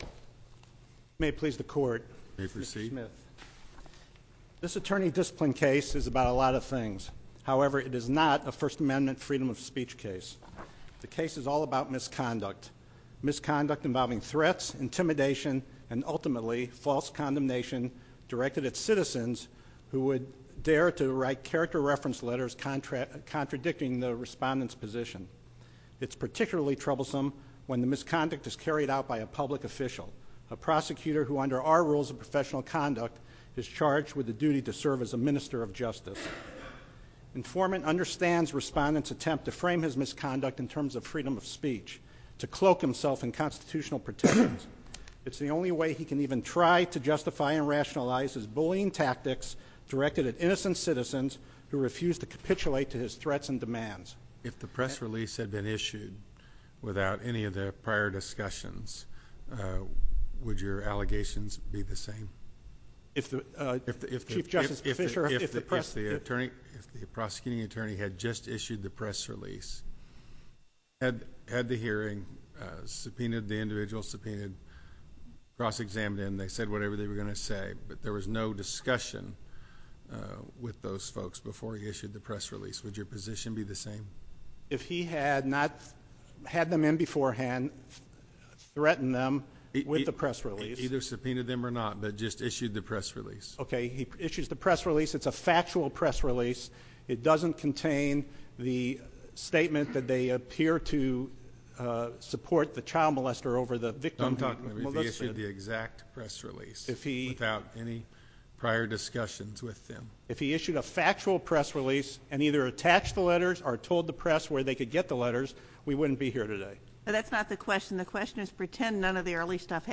MP3 audio file of oral arguments before the Supreme Court of Missouri in SC96830